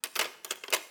SFX_Cutlery_04.wav